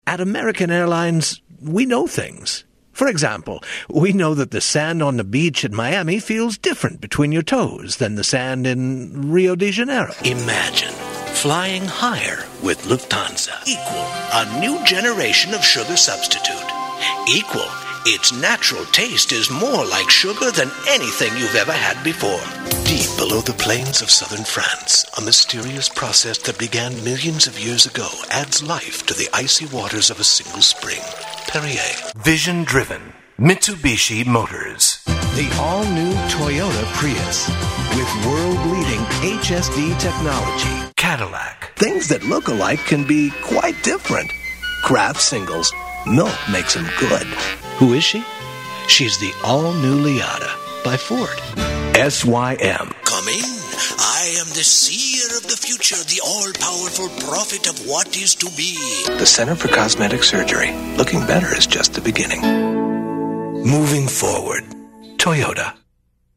A VOICE THAT\'S COMPLETELY DIFFERENT - WARM, GENUINE, FRIENDLY. APPROACHABLE, CONVERSATIONAL, YET AUTHORITATIVE, AND BELIEVABLE - AND A MASTER OF MANY DIALECTS AND ACCENTS, AS WELL.
Sprechprobe: Werbung (Muttersprache):